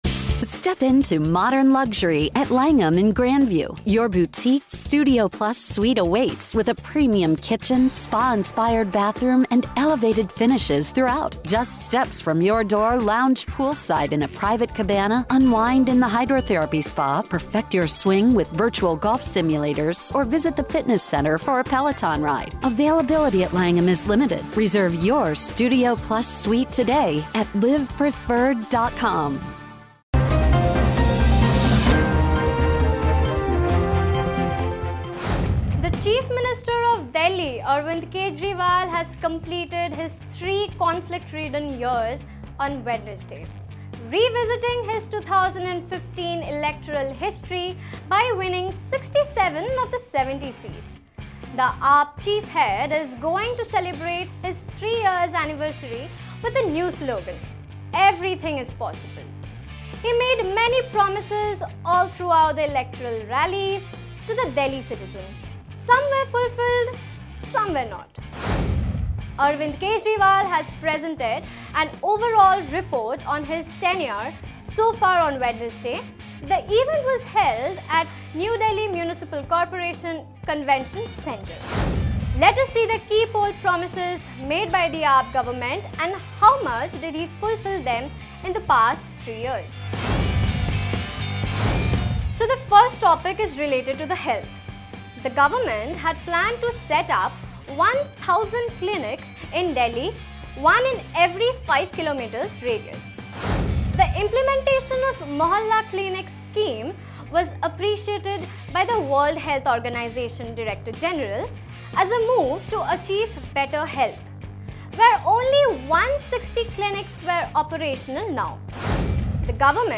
News Report / Has three years been KHAAS or AAM for Arvind Kejriwal